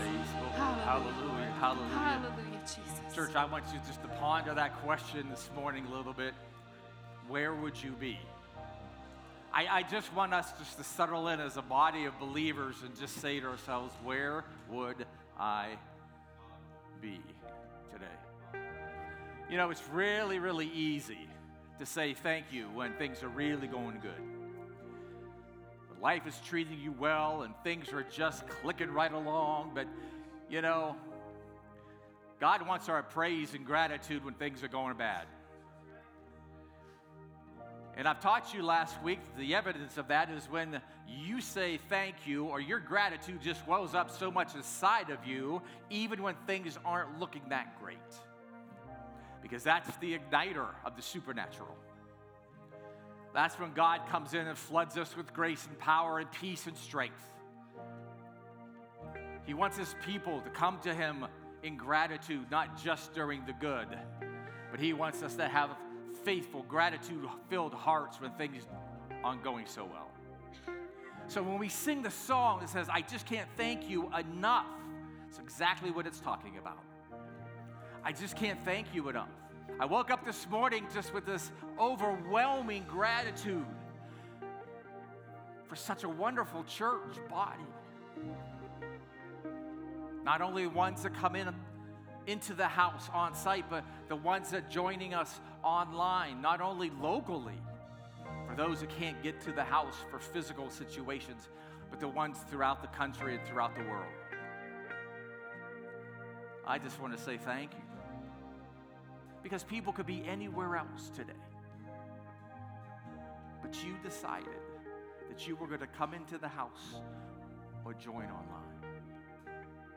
A message from the series "Gratitude in Motion."